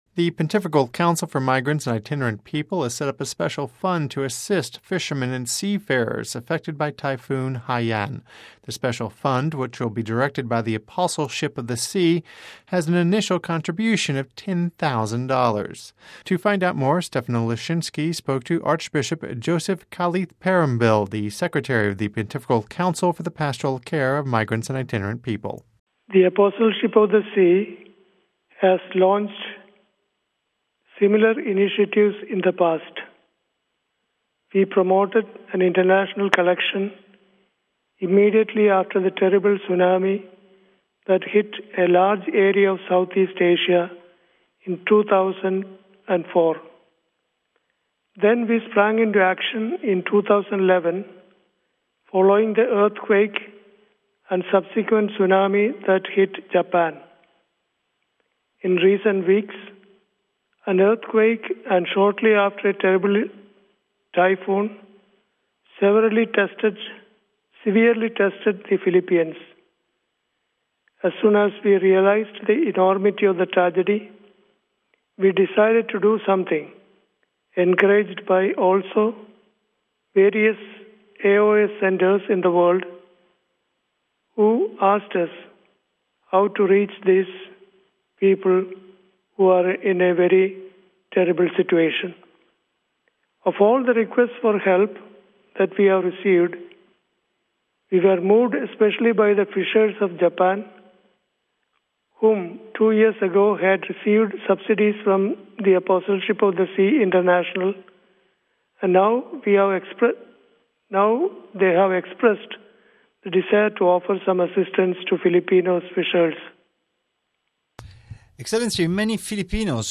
Vatican Radio Interview
of Archbishop Joseph Kalathiparambil